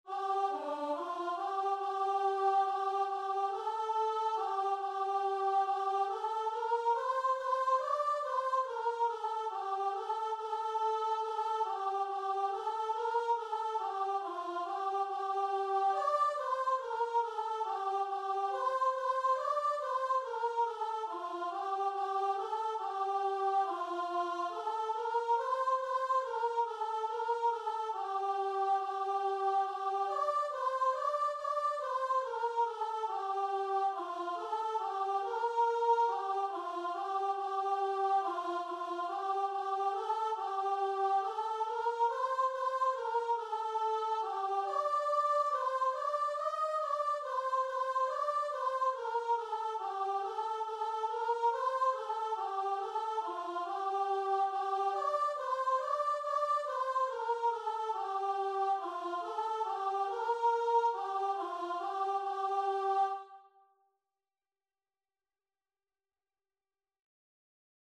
Free Sheet music for Choir
G minor (Sounding Pitch) (View more G minor Music for Choir )
Choir  (View more Easy Choir Music)
Christian (View more Christian Choir Music)